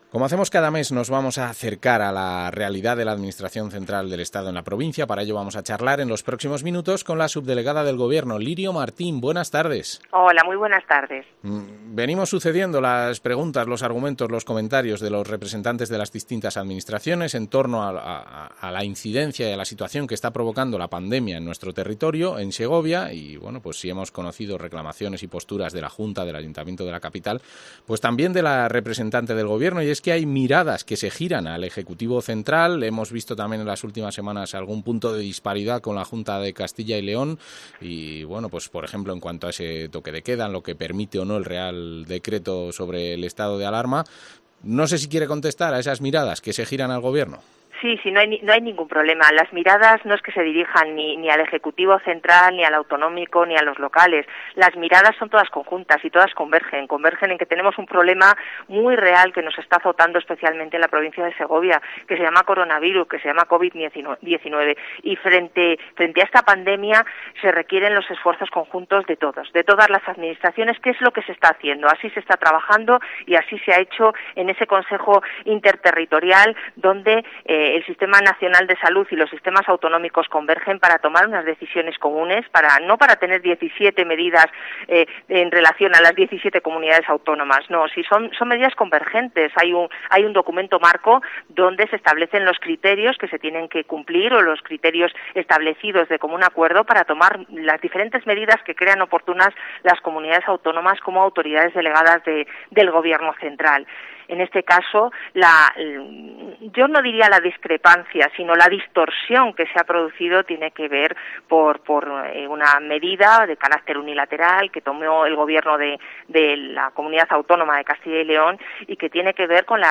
Entrevista con la subdelegada del Gobierno, Lirio Martín